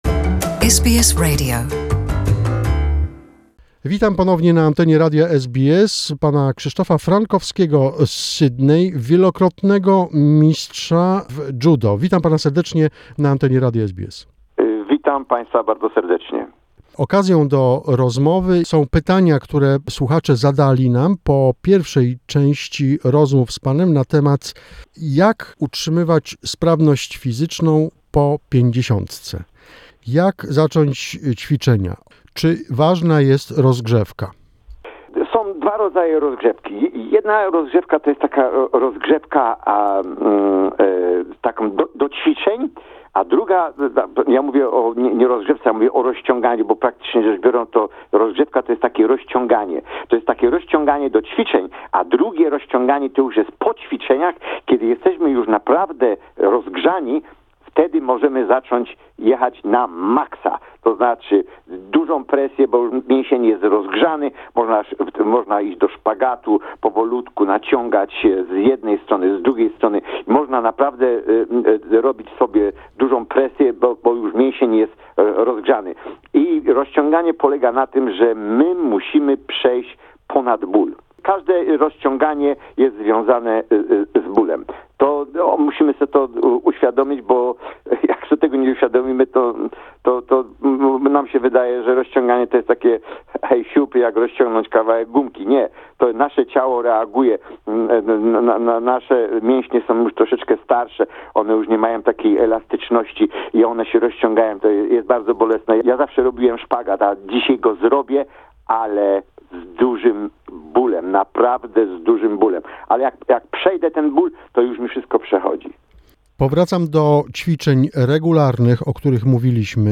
This is a part 2 of the interview.